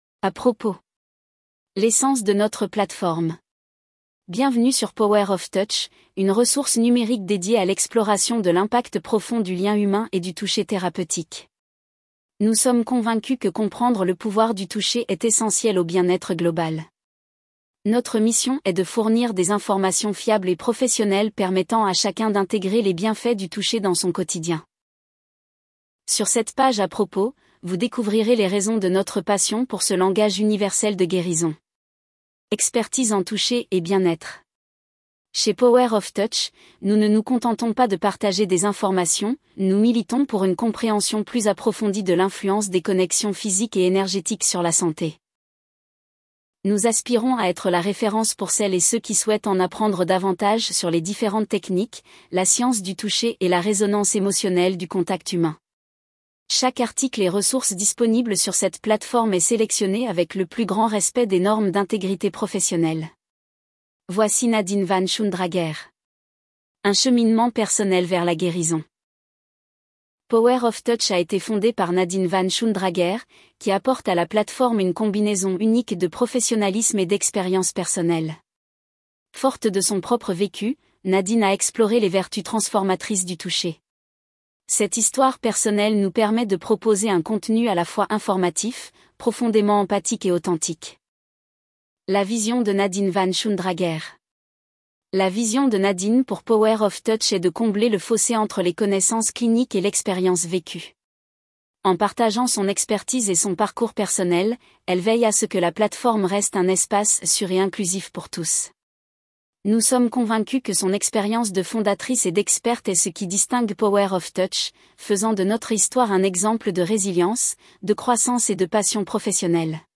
mp3-text-to-voice-a-propos-power-of-touch.mp3